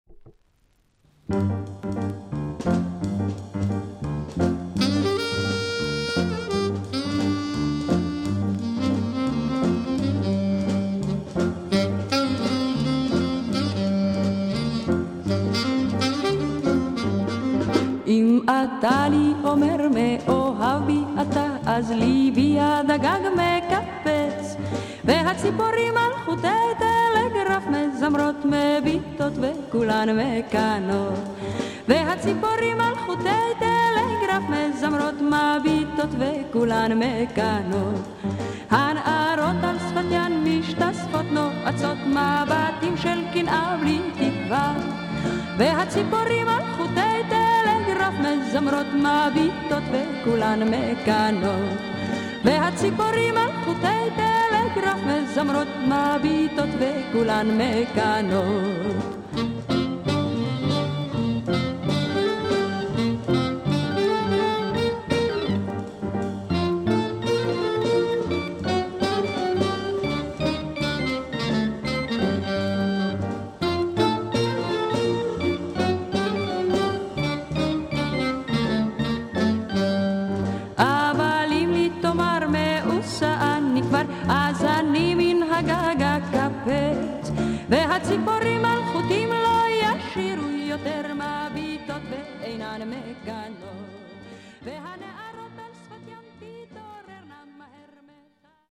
Israel Female vocal Jazz oriental pop LP